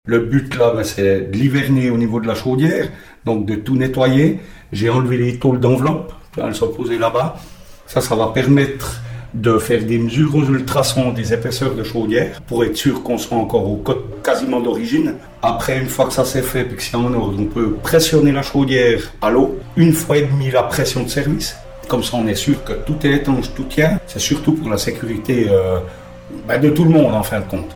Des-mesures-aux-ultrasons.mp3